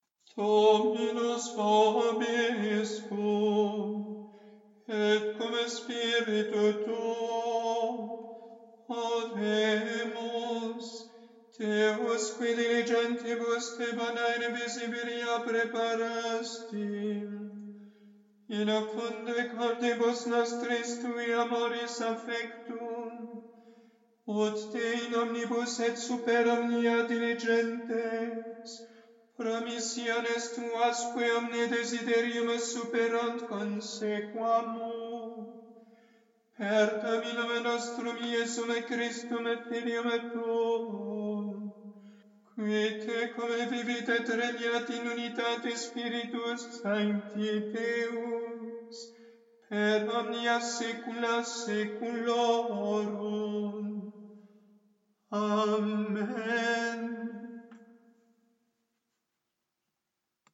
Oraison du 5e dimanche apr�s la Pentec�te NB.